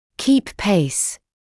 [kiːp peɪs][киːп пэйс]поддерживать темп, скорость